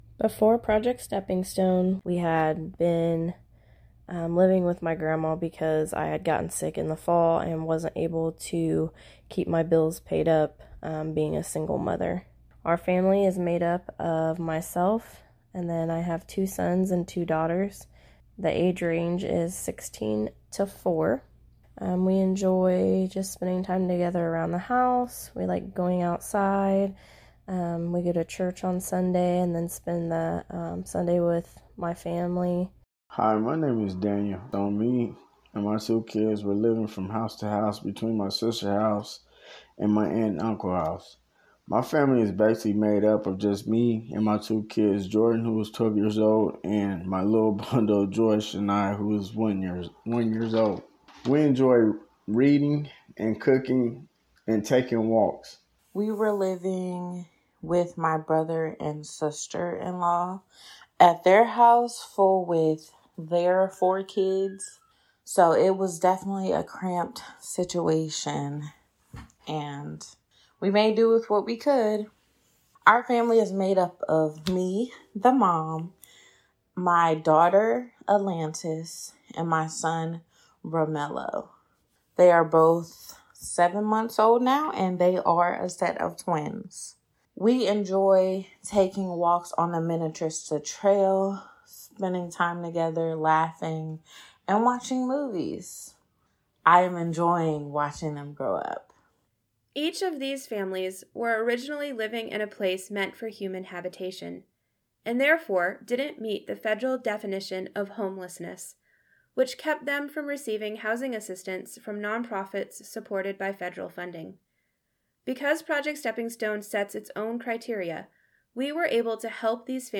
Presented here are the stories of three residents sharing how Project Stepping Stone has impacted their lives.